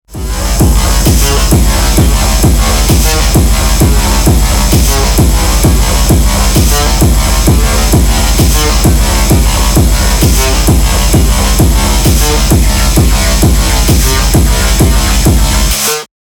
[Industrial Techno] Ein sanfter schubs auf Deine Hörmuscheln ;)
Das war ja schon platt Ok, habe künstlich wieder Transienten erzeugt mit Kompressor (Fabfilter Pro C) und Expander (Fabfilter ProMB).
Ja, dann EQ, Clipper und Limiter.
Sollte sich wie das Original anhören nur eben das bei diesem White Noise Snare auf Schlag 4 die Kick nicht ganz nach hinten rutscht. Das ist nur Summenbearbeitung und kann natürlich nicht das Problem 100% lösen.